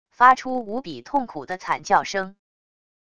发出无比痛苦的惨叫声wav音频